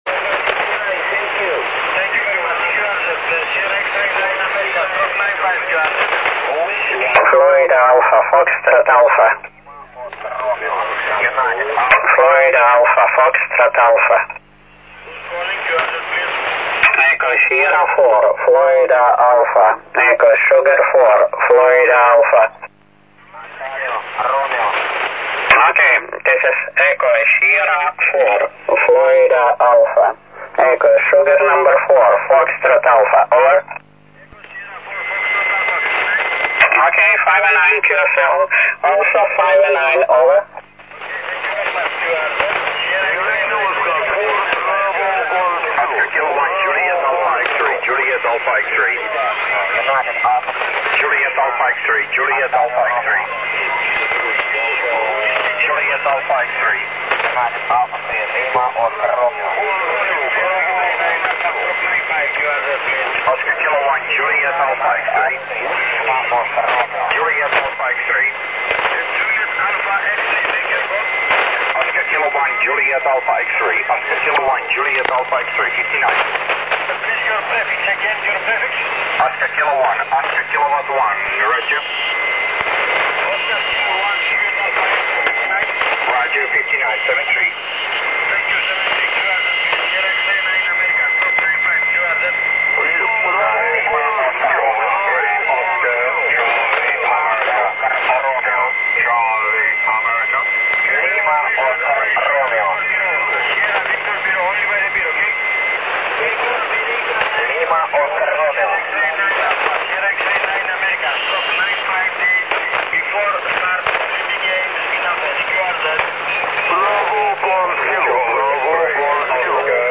CW recording on 80 meter band using SSB filter. QTH is a noisy place in Vantaa Finland